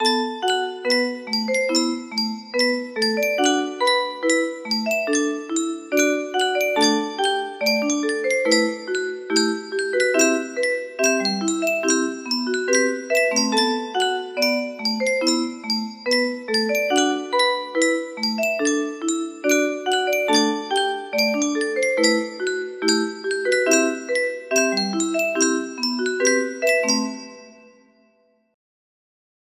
It's not that accurate, more like an interpretation